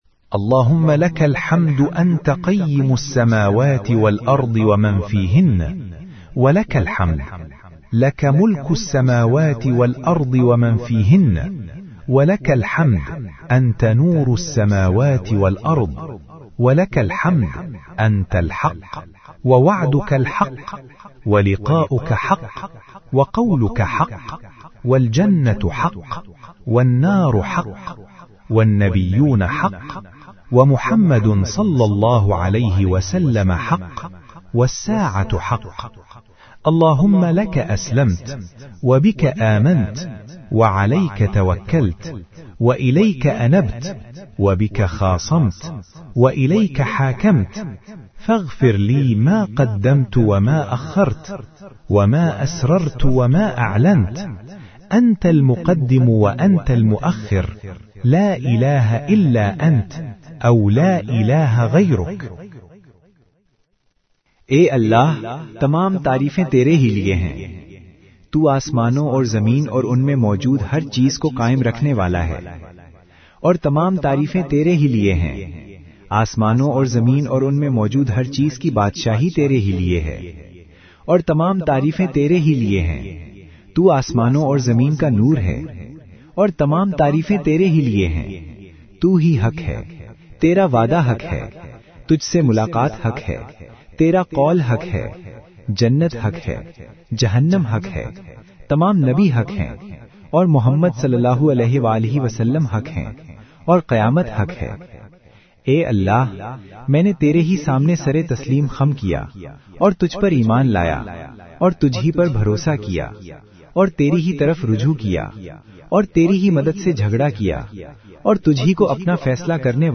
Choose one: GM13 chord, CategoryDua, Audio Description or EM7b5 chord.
CategoryDua